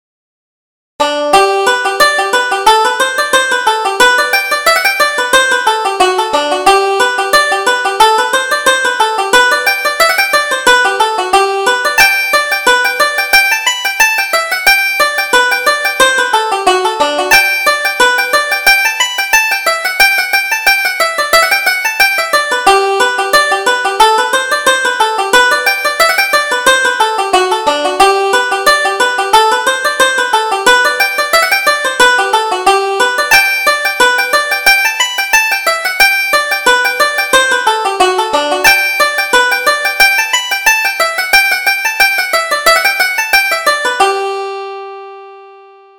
Reel: The Traveller